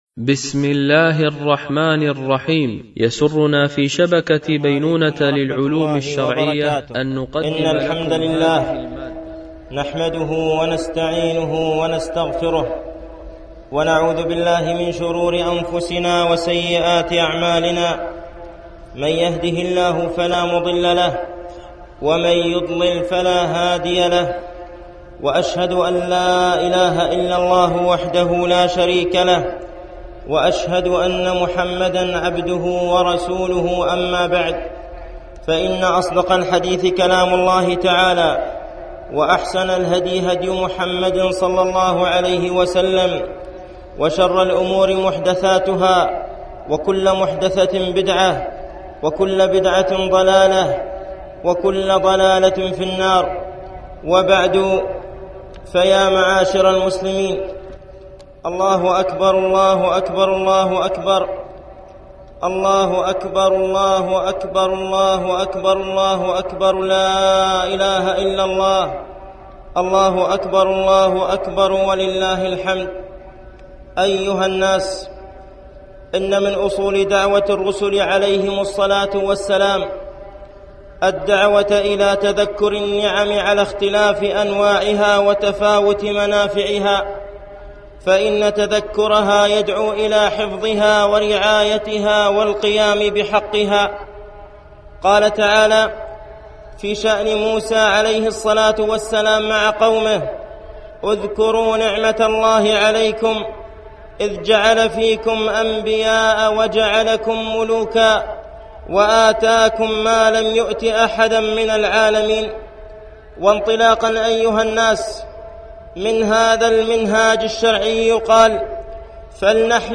خطبة عيد الفطر لعام 1435